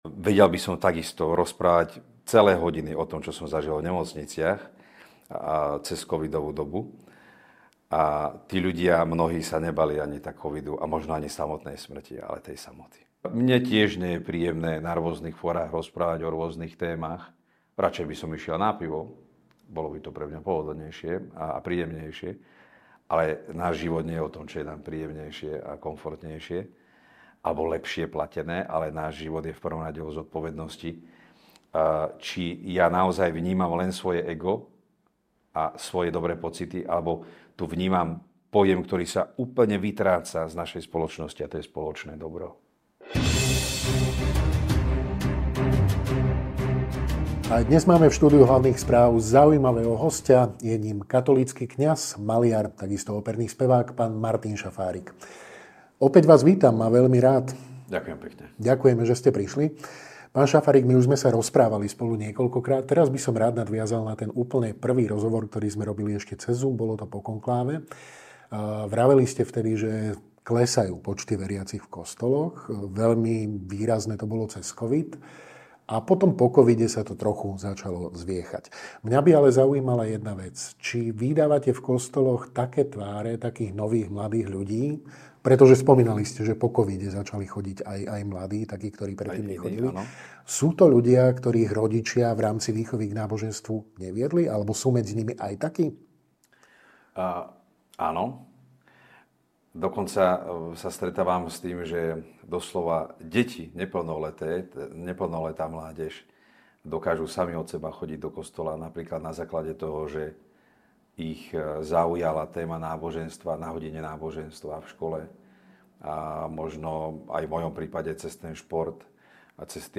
NAŽIVO
Dnes v rozhovore pokračujeme a reč bude najmä o význame učiteľa, autority a viery v živote dieťaťa.